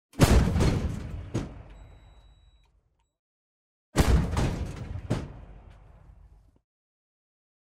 Tankskott | Ladda ner ljudeffekter .mp3.
Tankskott | ljudeffekt .mp3 | Ladda ner gratis.